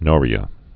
(nôrē-ə)